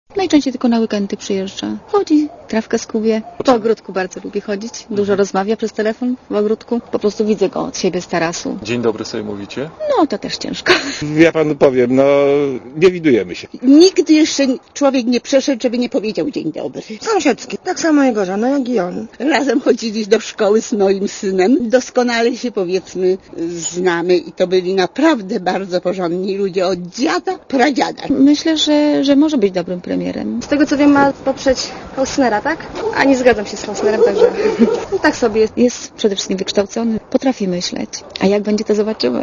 O Marku Belce opowiadają jego sąsiedzi (159 KB)